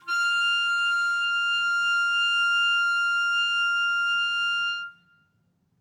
Clarinet / susLong
DCClar_susLong_F#5_v1_rr1_sum.wav